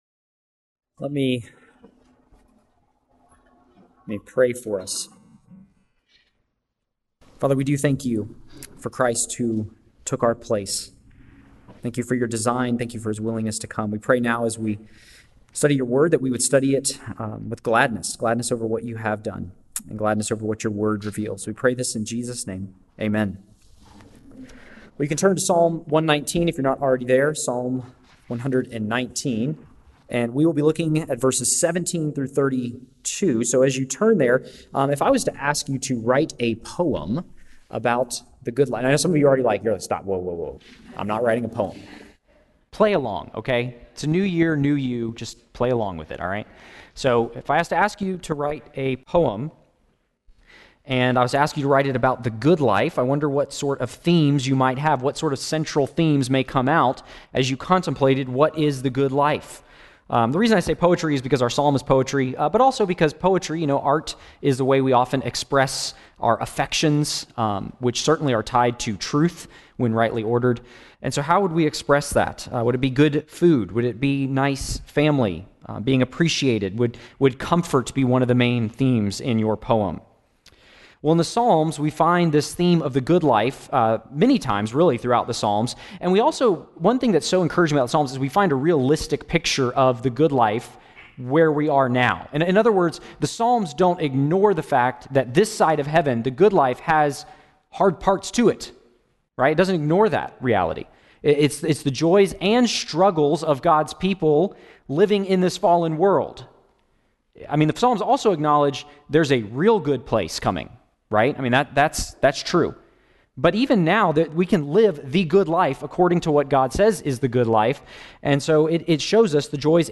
2022-01-02-AM-Sermon.mp3